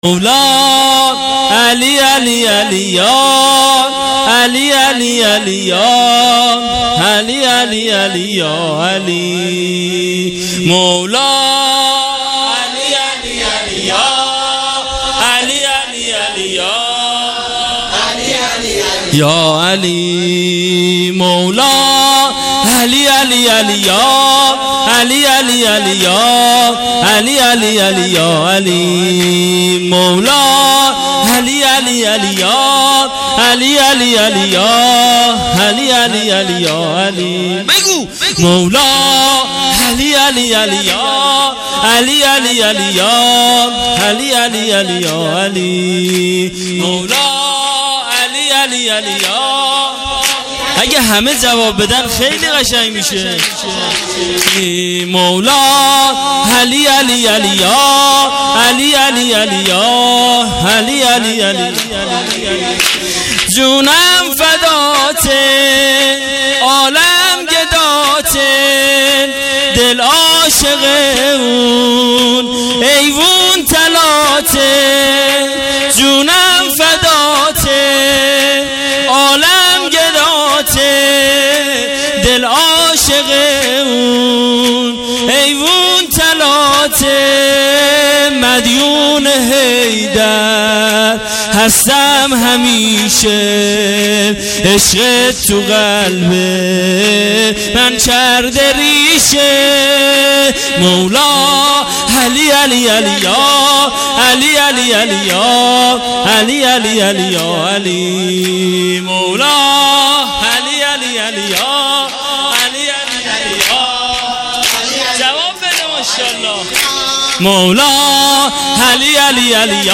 جشن عید غدیر خم-8 شهریور ماه 97